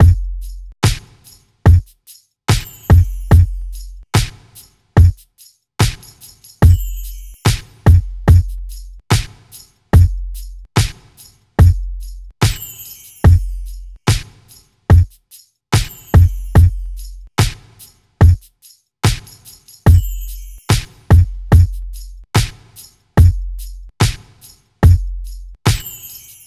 02 drums A.wav